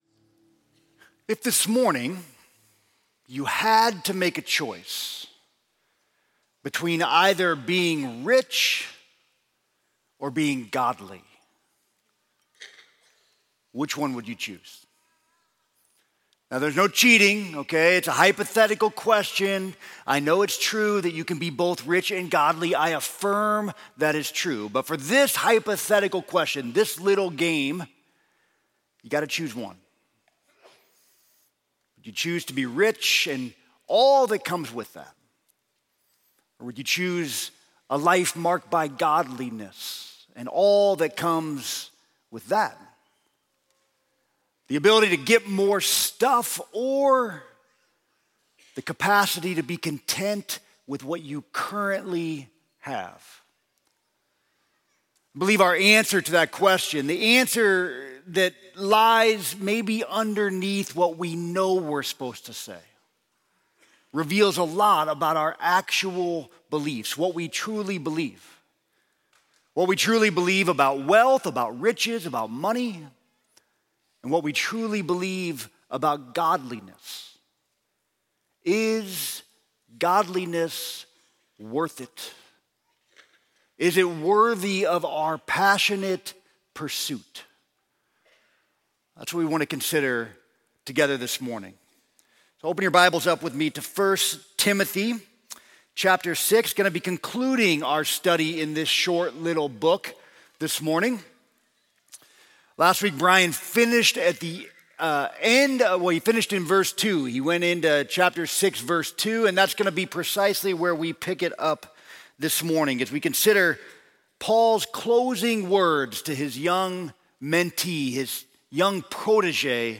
Sermon: The Great Gain of Godliness